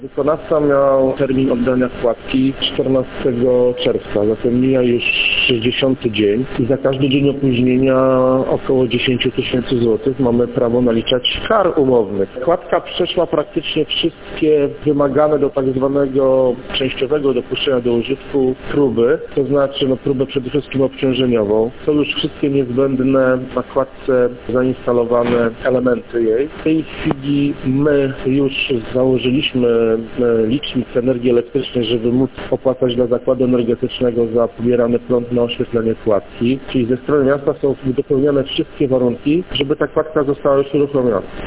– Winny, w tym przypadku, jest wykonawca prac, który nie złożył nam jeszcze dokumentacji technicznej – mówi Cezary Piórkowski, zastępca burmistrza Giżycka.